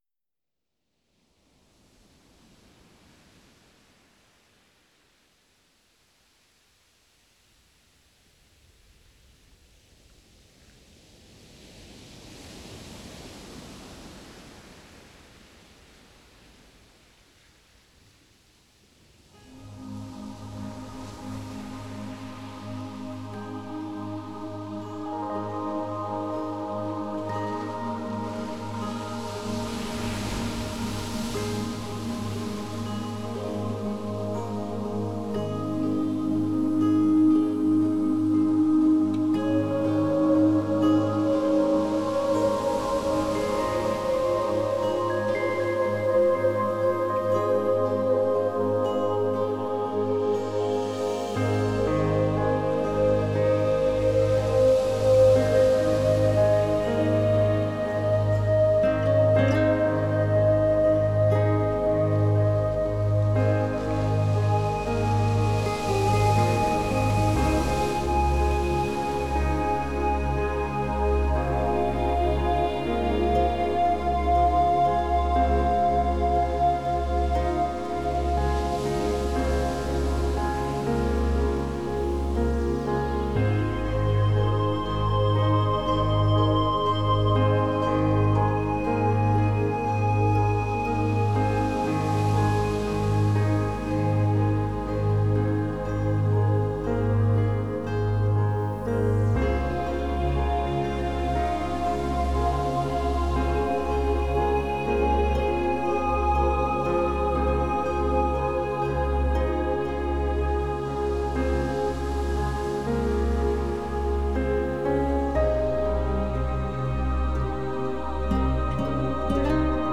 New age Релакс Медитативная музыка Нью эйдж Музыка релакс